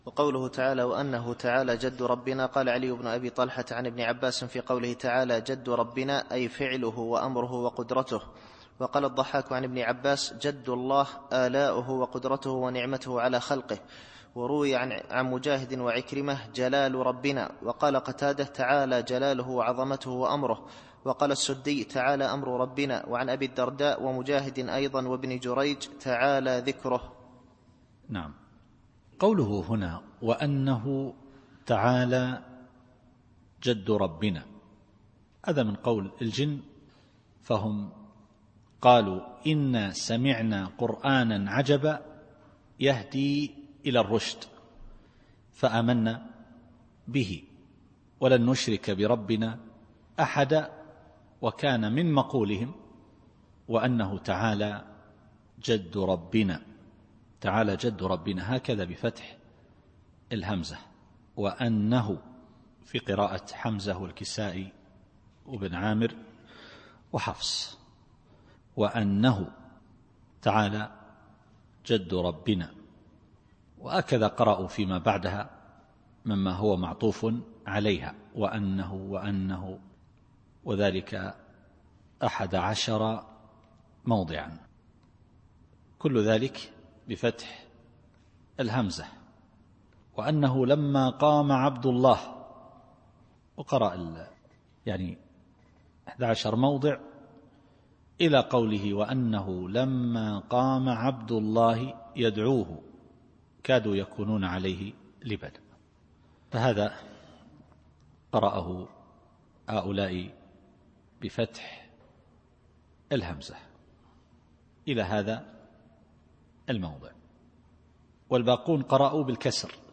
التفسير الصوتي [الجن / 3]